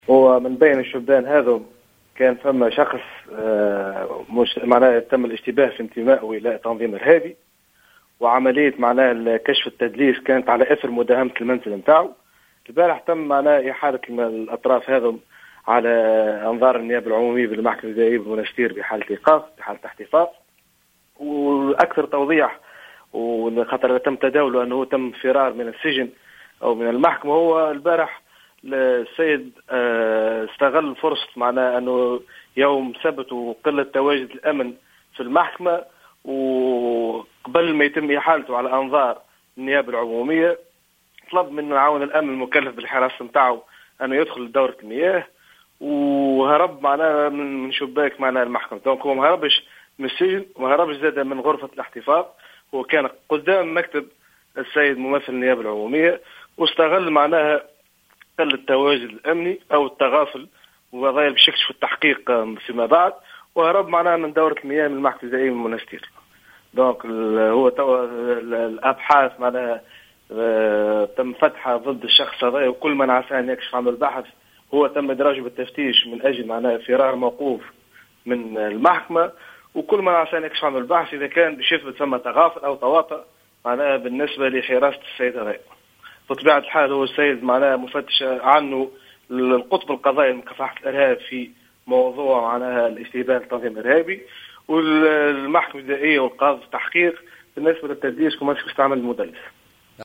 في تصريح للجوهرة أف أم